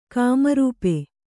♪ kāmarūpe